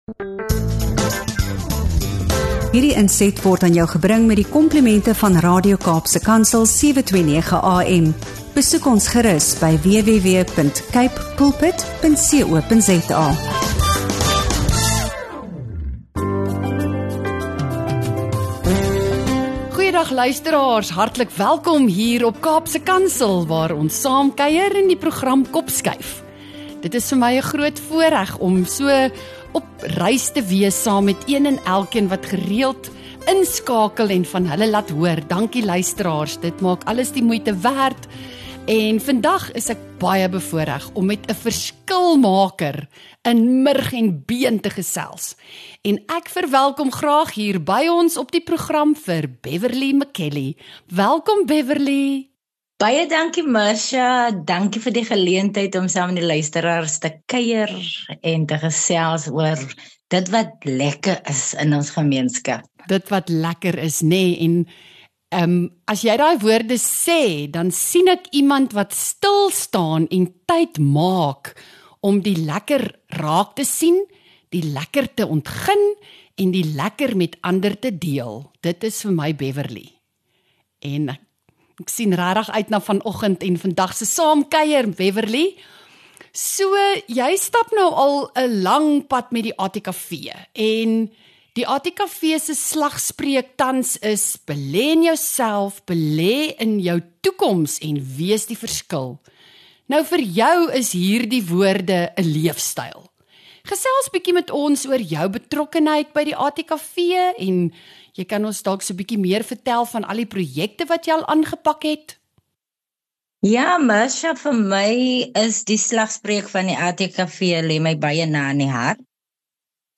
28 Feb KOPSKUIF - Taal, Identiteit en Lewende Erfenis | ’n Gesprek